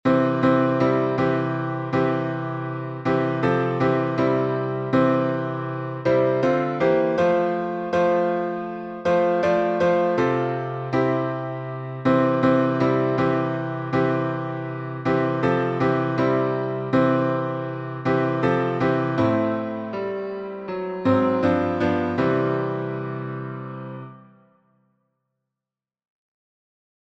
Folk melody from India Key signature: C major (no sharps or flats) Time signature: 4/4